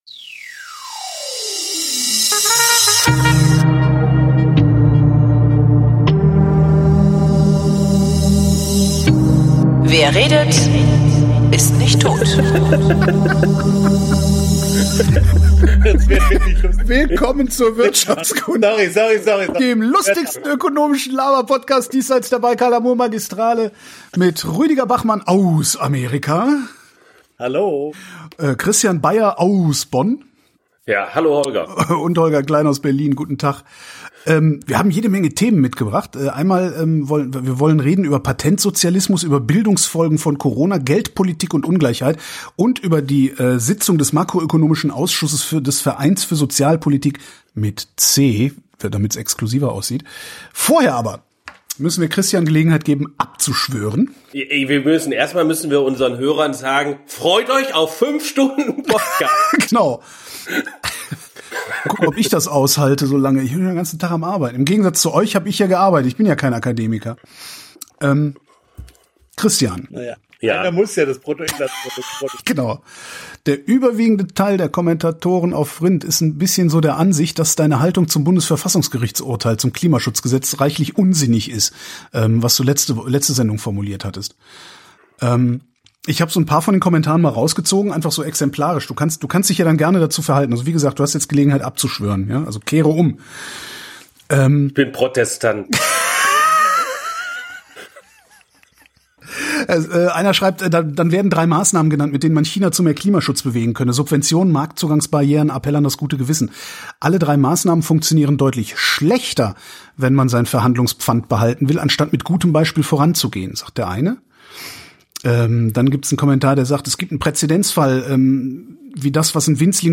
Keynesianismus , Karl Schiller , Ludger Wößmann: Folgekosten ausbleibenden Lernens Das gelegentliche Brummen ist ein amerikanischer Rasenmäher!